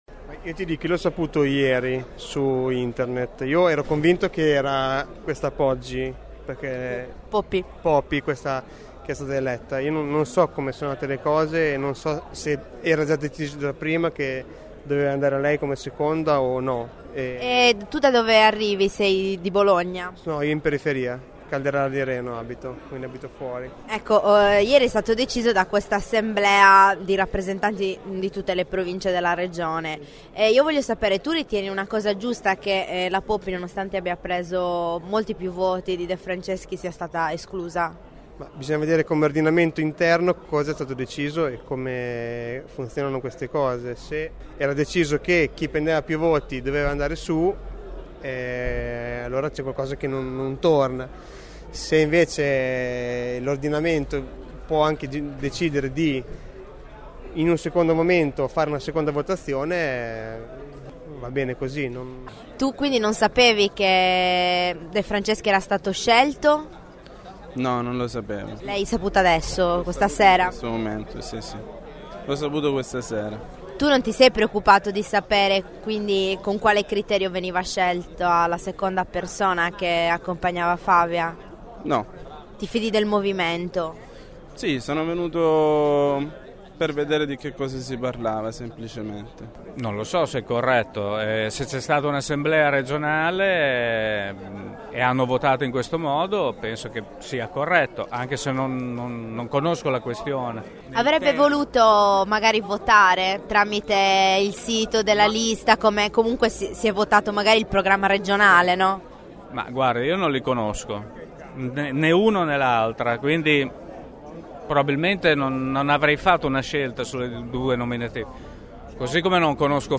Nella serata di ieri si è svolta l’assemblea provinciale dei grillini a cui hanno partecipato circa 250 persone, un’affluenza inaspettata secondo gli organizzatori.